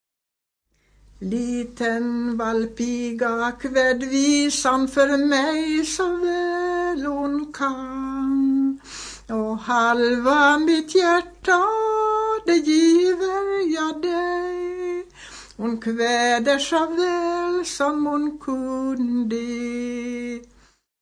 Hon sjunger med tydlig text och tonande konsonanter.
Typ av visa: ballad SMB 182